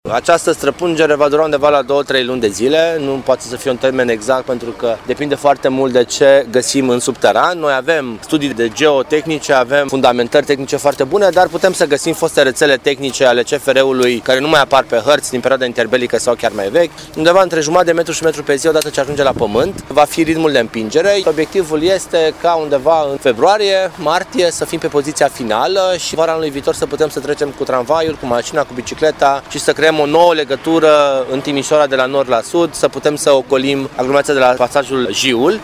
Viceprimarul Ruben Lațcău a explicat că această fază ar trebui să fie terminată în primăvară, urmând ca vara viitoare sau cel târziu în toamna anului viitor să se poată circula prin pasaj.